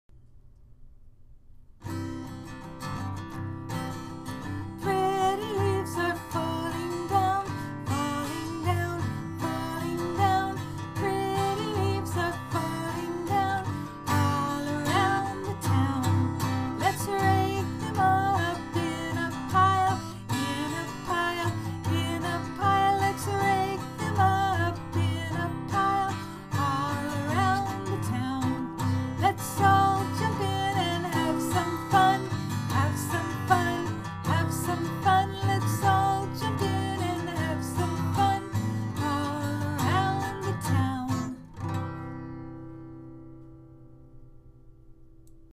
Tune: “London Bridge”